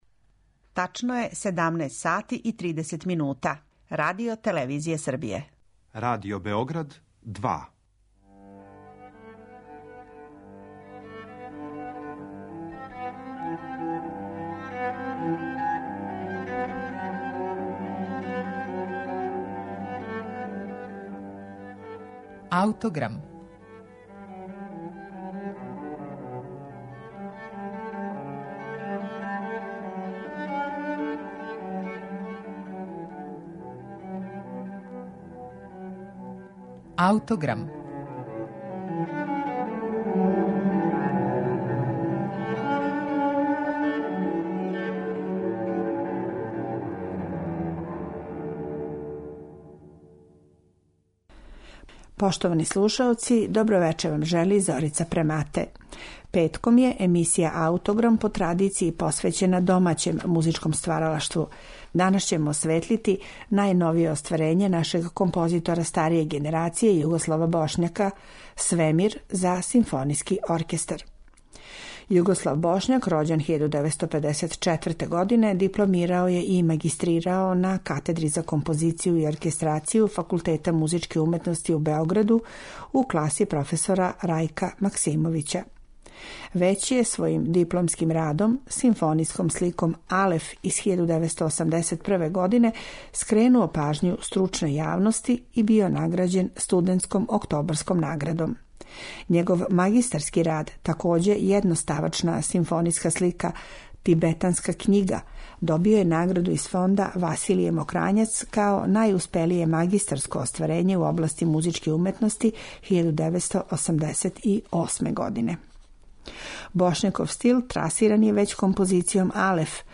оркестарско остварење